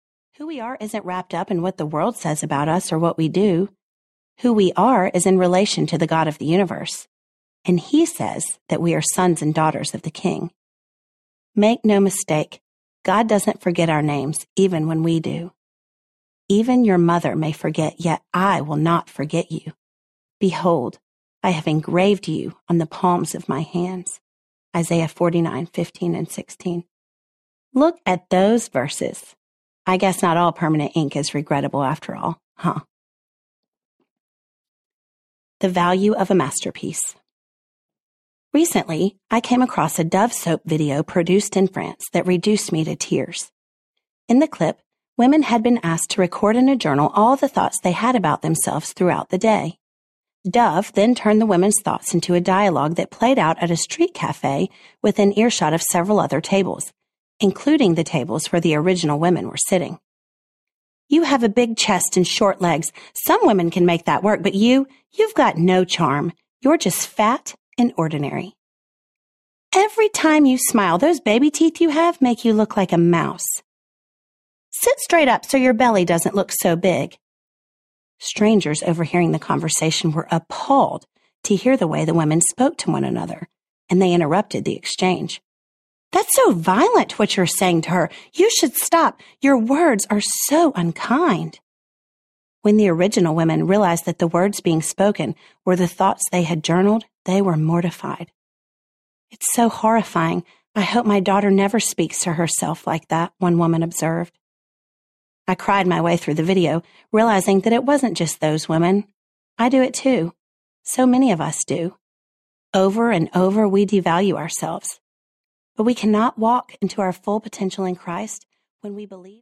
Curious Faith Audiobook
7.0 Hrs – Unabridged